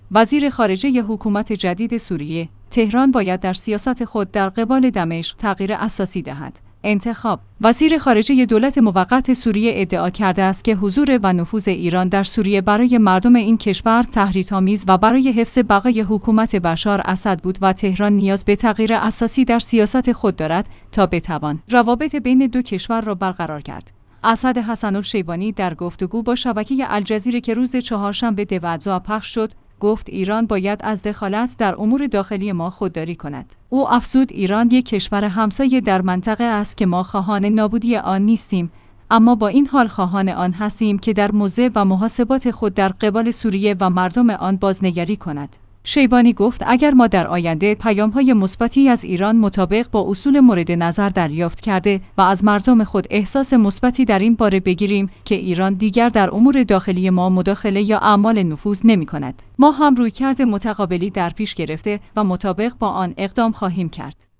انتخاب/ وزیر خارجه دولت موقت سوریه ادعا کرده است که حضور و نفوذ ایران در سوریه برای مردم این کشور تحریک‌آمیز و برای حفظ بقای حکومت بشار اسد بود و تهران نیاز به تغییر اساسی در سیاست خود دارد تا بتوان روابط بین دو کشور را برقرار کرد. اسعد حسن الشیبانی در گفتگو با شبکه الجزیره که روز چهارشنبه ۱۲ دی پخش